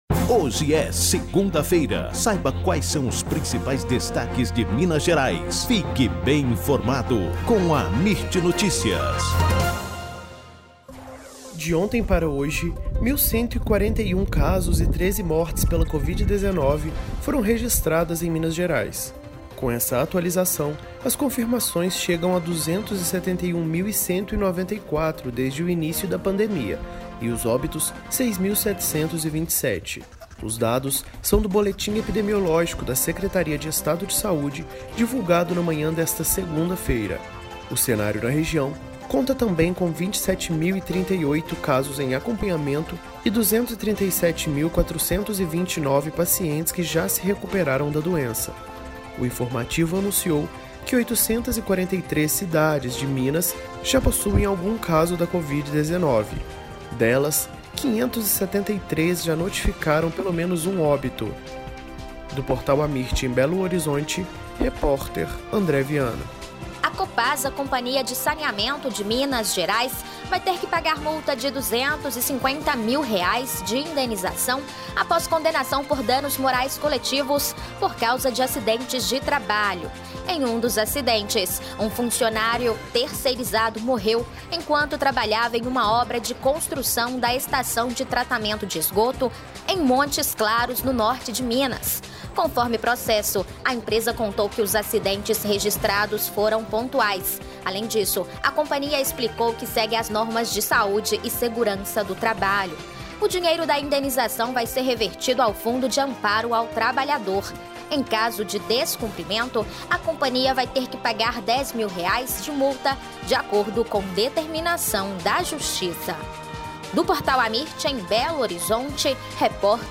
AMIRT Amirt Notícias Destaque Notícias em áudio Rádio e TelevisãoThe estimated reading time is less than a minute